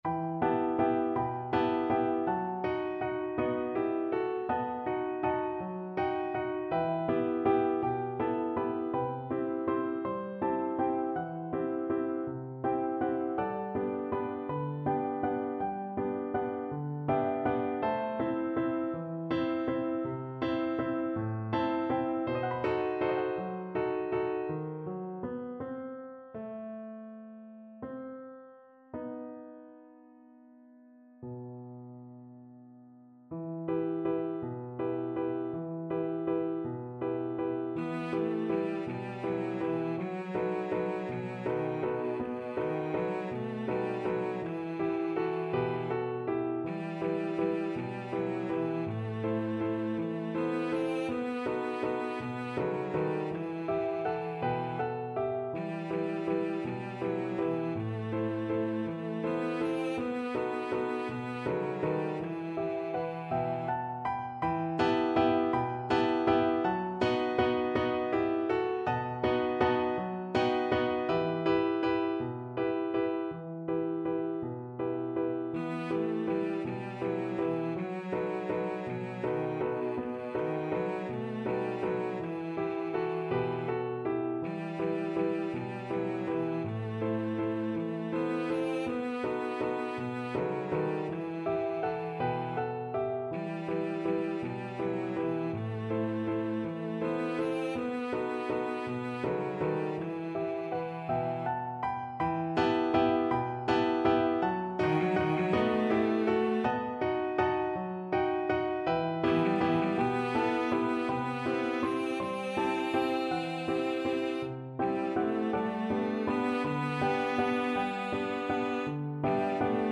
Classical Lysenko, Mykola Nich Yaka Misyachna (A Moonlight Night) Cello version
E minor (Sounding Pitch) (View more E minor Music for Cello )
3/4 (View more 3/4 Music)
One in a bar .=c.54
Cello  (View more Easy Cello Music)
Classical (View more Classical Cello Music)